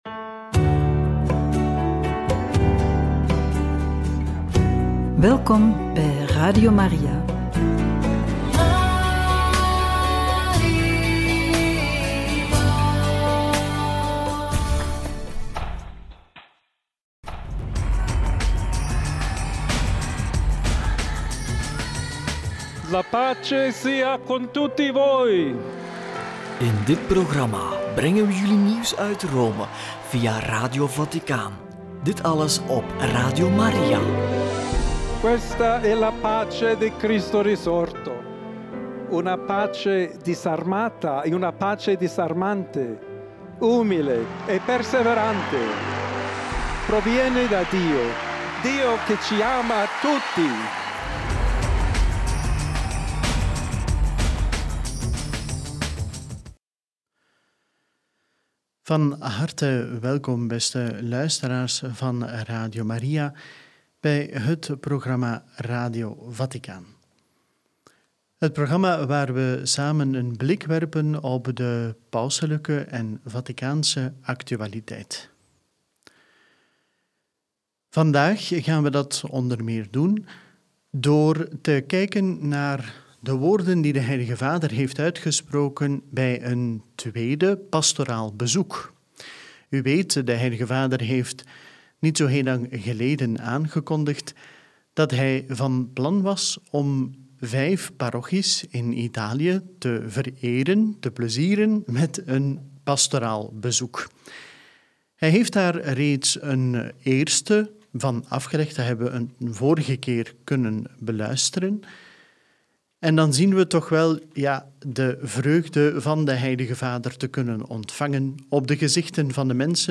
Homilie Sacre Cuore di Gesù – Angelus 22/02 – Toespraak tot Romeinse clerus – Radio Maria
homilie-sacre-cuore-di-gesu-angelus-22-02-toespraak-tot-romeinse-clerus.mp3